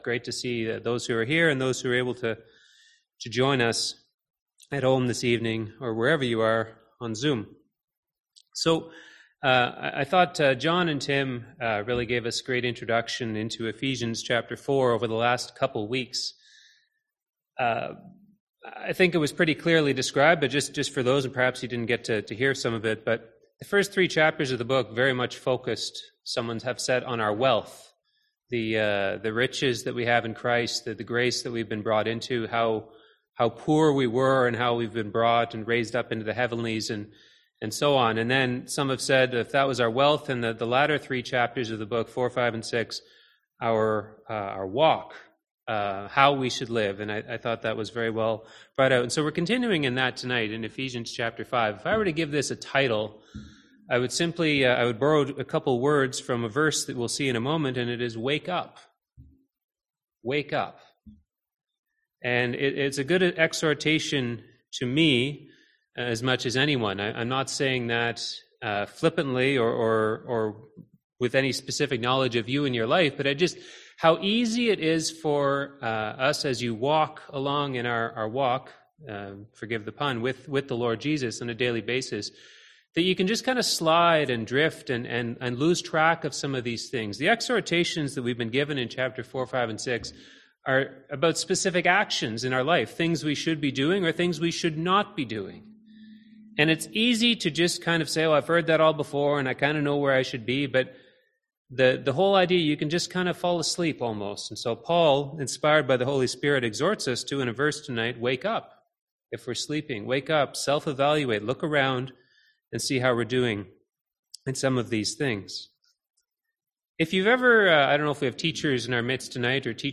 Series: Ephesians 2022 Passage: Ephesians 5:1-21 Service Type: Seminar